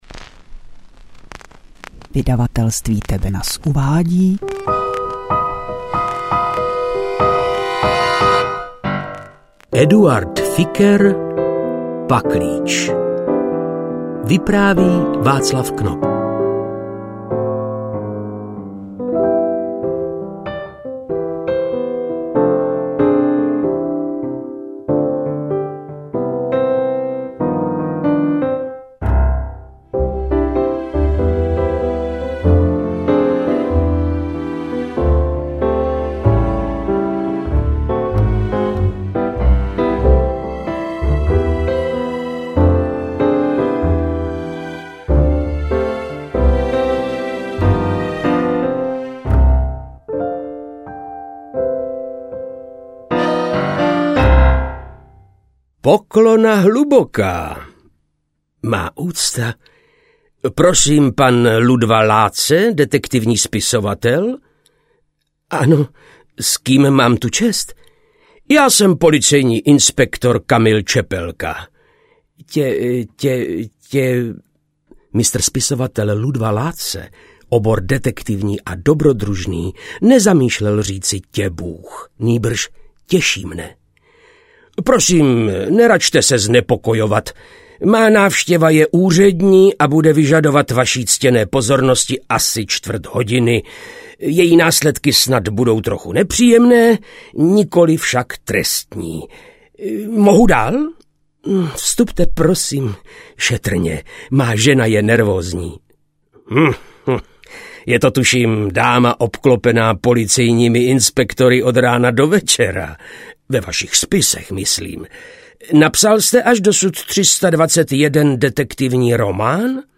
Interpret:  Václav Knop
AudioKniha ke stažení, 25 x mp3, délka 6 hod. 38 min., velikost 365,1 MB, česky